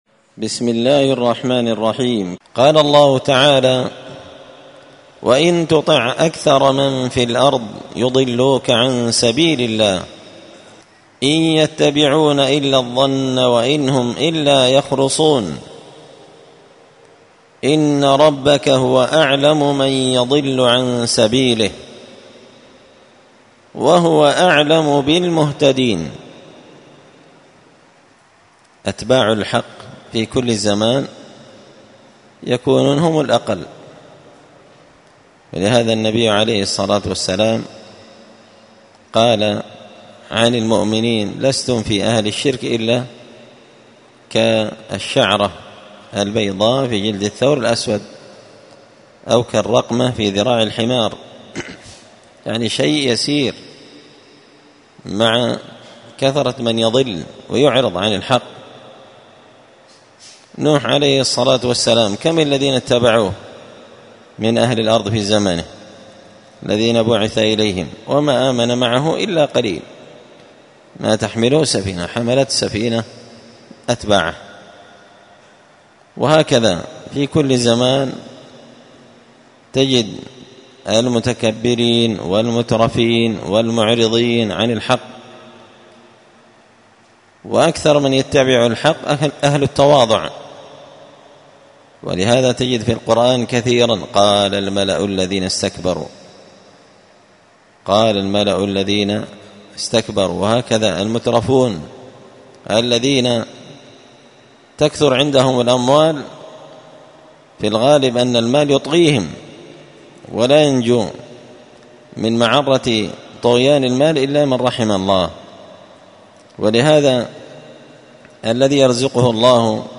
مختصر تفسير الإمام البغوي رحمه الله ـ الدرس 347 (الدرس 57 من سورة الأنعام)
مختصر تفسير الإمام البغوي رحمه الله الدرس 347